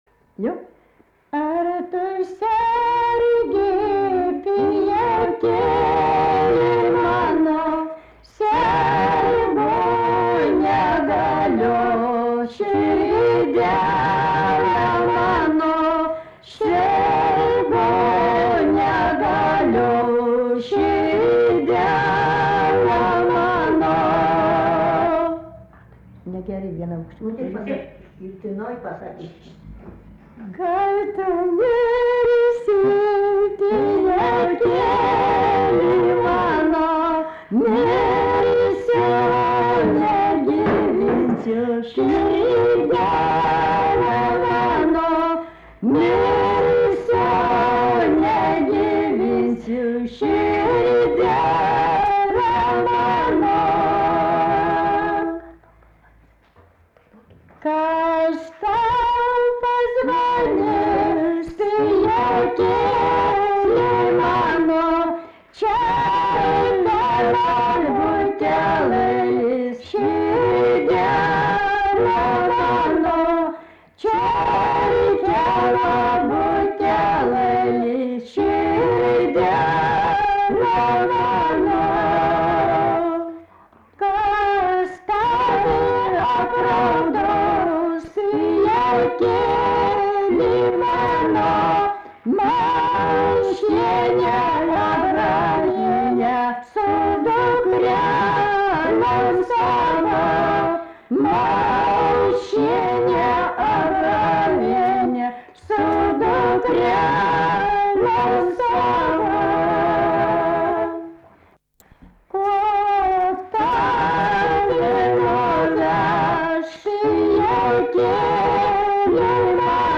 daina, šeimos
Lyduokiai
vokalinis